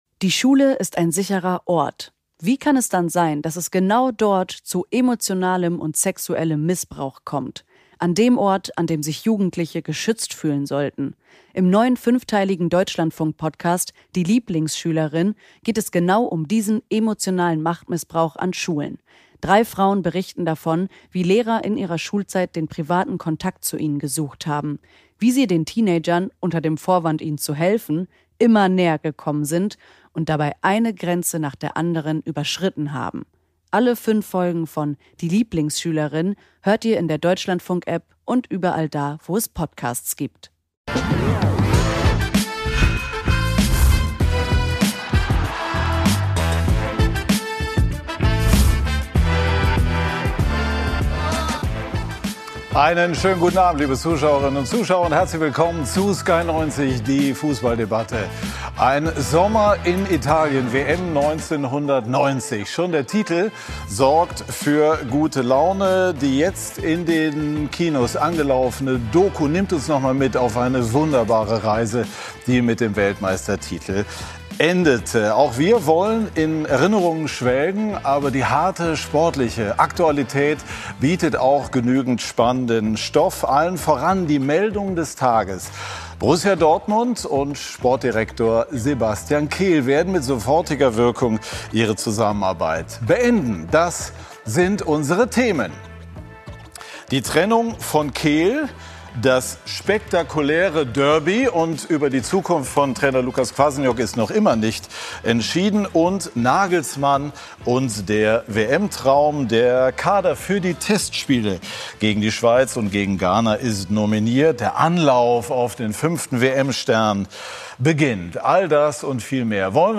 Kontrovers, unterhaltsam, meinungsbildend – mit Sky90 präsentiert Sky den umfassendsten Fußball-Live-Talk Deutschlands. Immer sonntags ab 18:00 Uhr begrüßt Moderator Patrick Wasserziehr kompetente Gäste im Sky Studio.